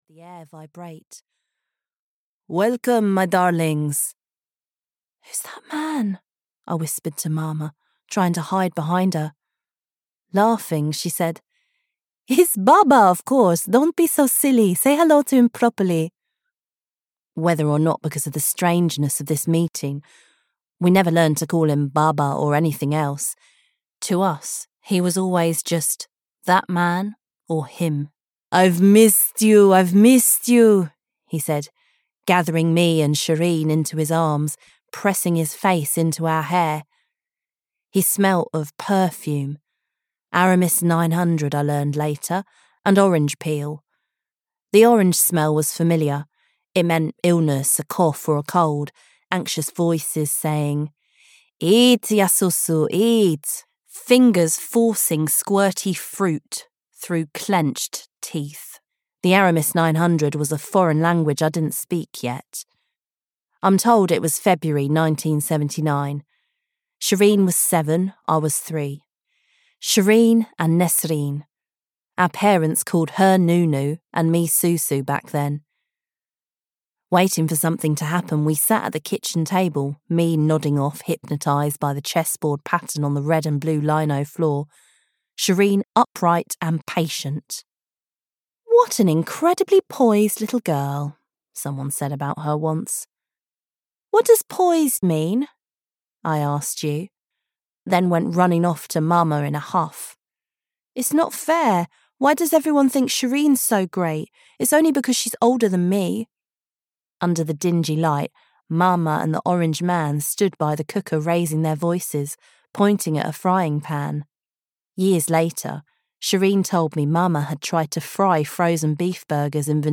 Other Names, Other Places (EN) audiokniha
Ukázka z knihy